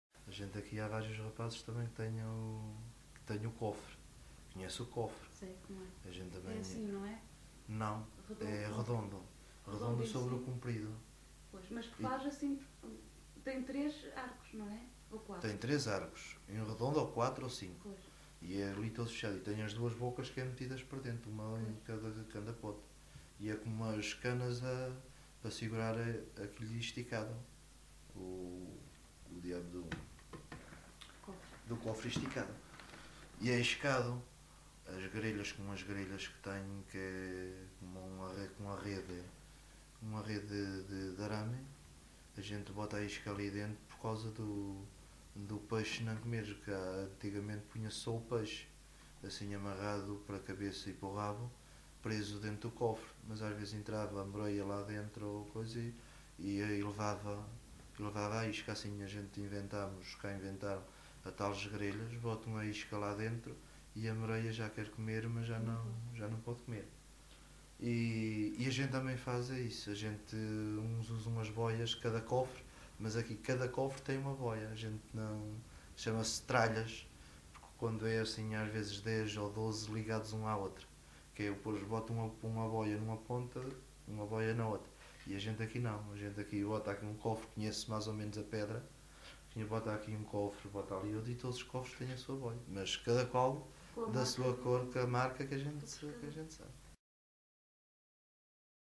LocalidadeMadalena (Madalena, Horta)